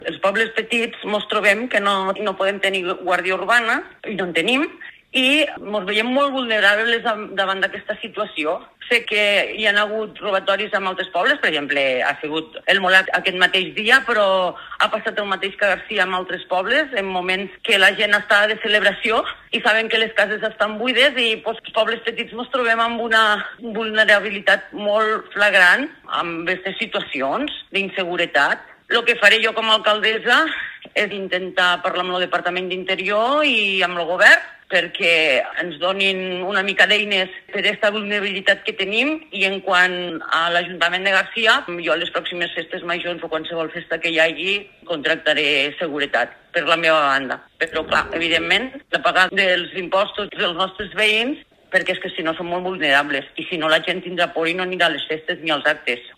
L’alcaldessa de Garcia lamenta la situació i explica els passos que seguirà per trobar solucions als robatoris